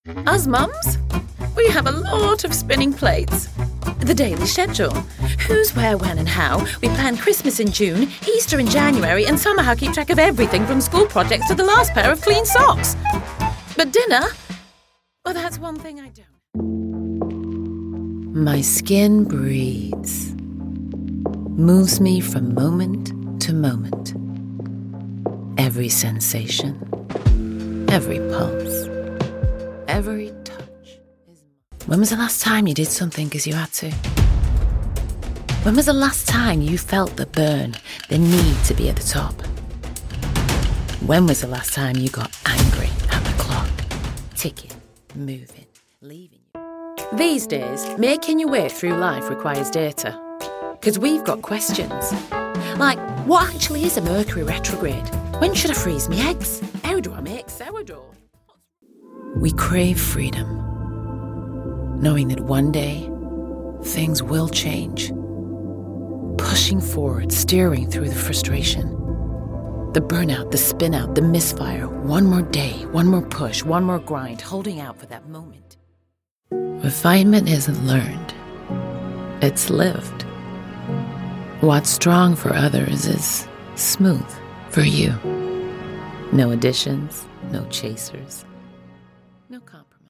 Victoria_Ekanoye_Clipped_Mixed_Accent_Demo_2025.mp3